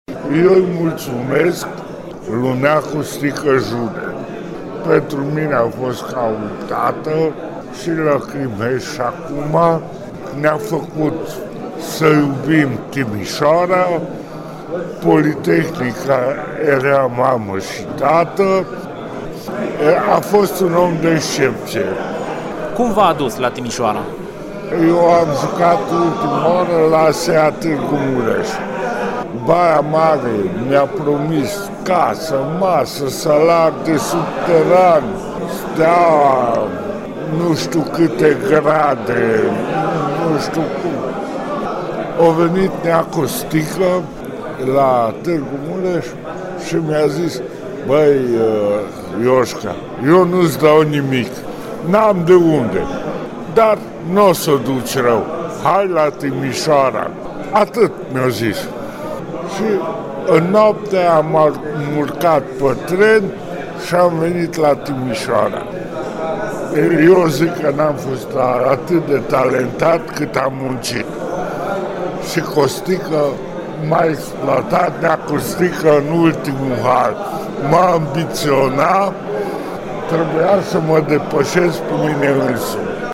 Într-un fragment dintr-un interviu realizat acum aproximativ trei ani